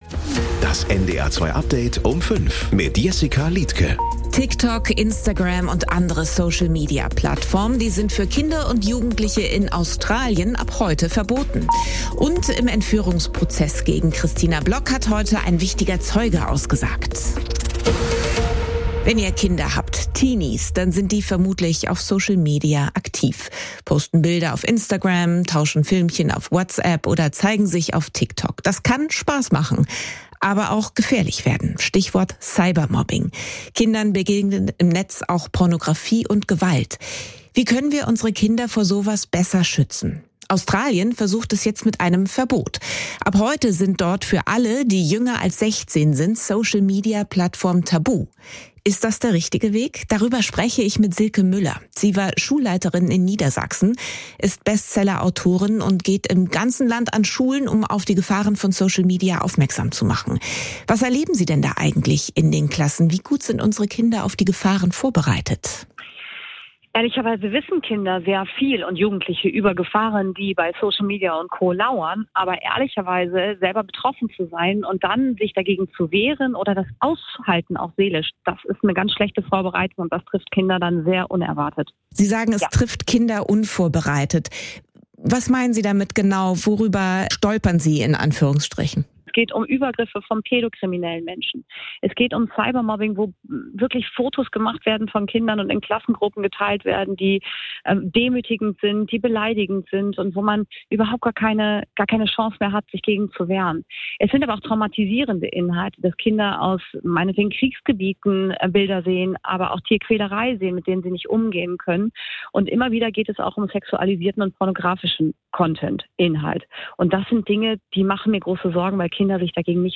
… continue reading 107 episod # Nachrichten # NDR 2 # NDR # News # Kurier Um 5 # Update Um 5